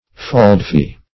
Search Result for " faldfee" : The Collaborative International Dictionary of English v.0.48: Faldfee \Fald"fee`\, n. [AS. fald (E.fold) + E. fee.